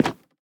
Minecraft Version Minecraft Version 1.21.5 Latest Release | Latest Snapshot 1.21.5 / assets / minecraft / sounds / block / cherrywood_trapdoor / toggle1.ogg Compare With Compare With Latest Release | Latest Snapshot
toggle1.ogg